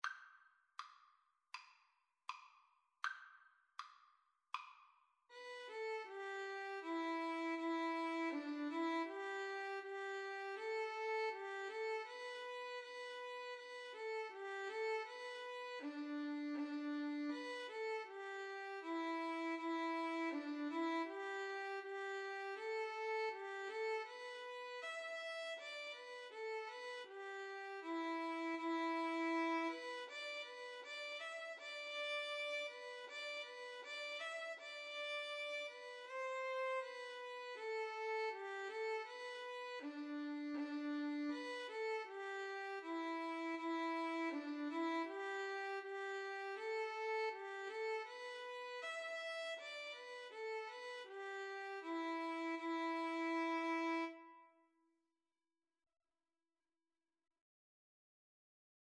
Andante Espressivo = c. 80
4/4 (View more 4/4 Music)
Violin Duet  (View more Easy Violin Duet Music)
Traditional (View more Traditional Violin Duet Music)